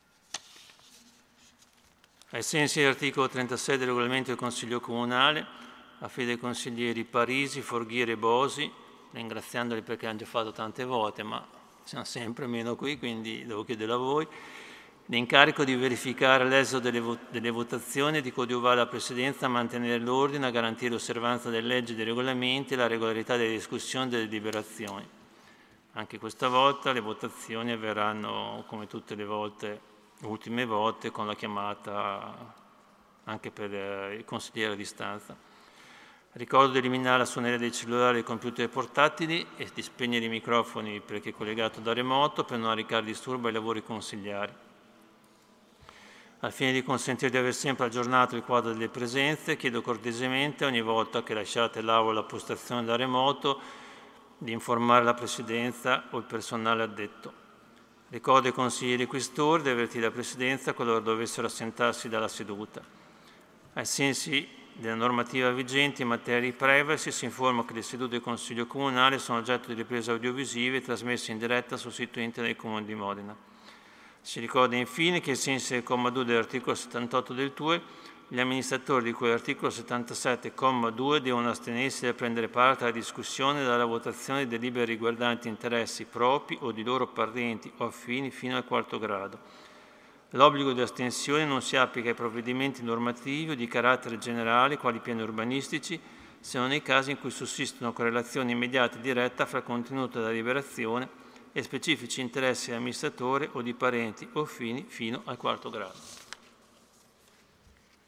Seduta del 23/07/2020 Apre i lavori del Consiglio Comunale